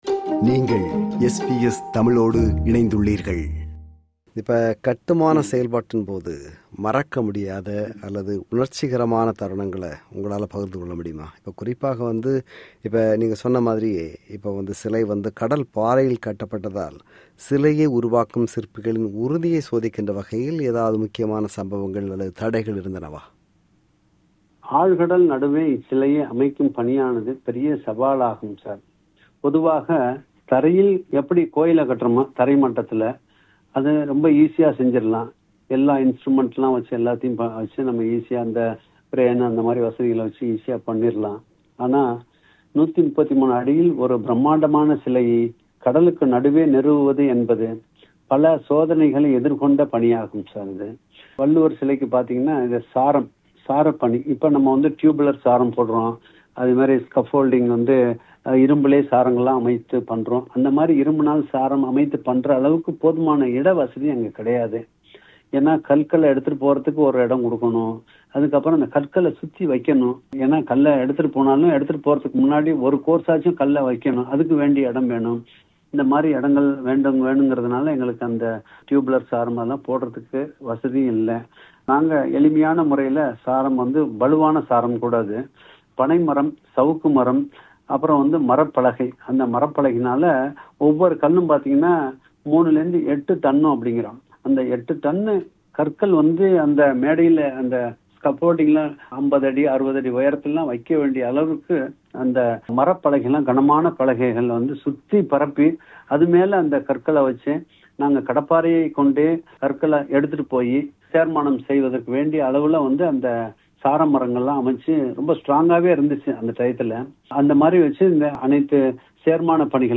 Interview Part: 2